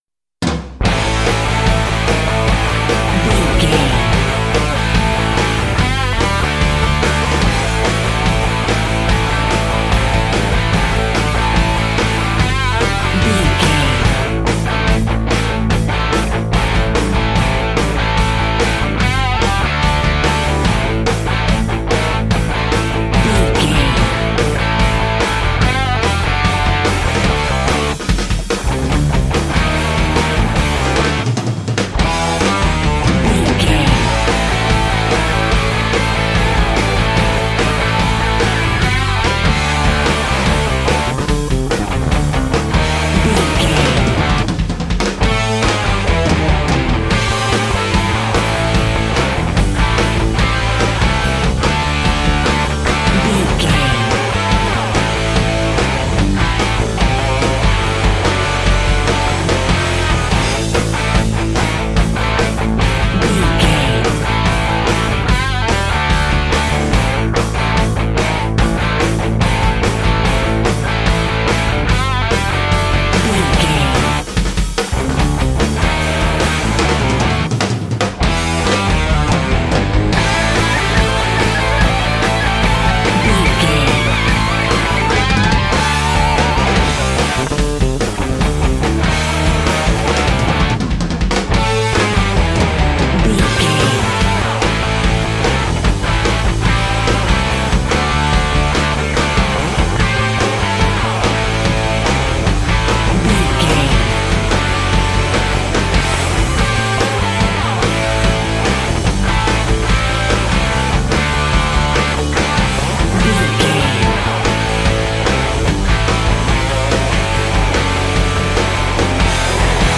Ionian/Major
DOES THIS CLIP CONTAINS LYRICS OR HUMAN VOICE?
WHAT’S THE TEMPO OF THE CLIP?
Fast
drums
electric guitar
bass guitar
pop rock
hard rock
lead guitar
aggressive
energetic
intense
nu metal
alternative metal